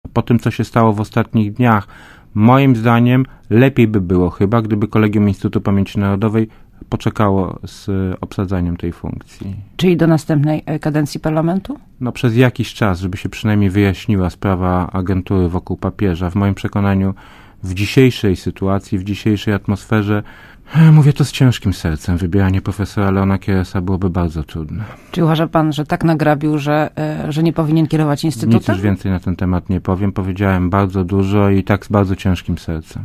Kolegium IPN powinno wstrzymać się z obsadzaniem fotela szefa Instytutu Pamieci Narodowej na drugą kadencję - mówi Gość Radia ZET Jan Rokita z Platformy Obywatelskiej.
Mówi Jan Rokita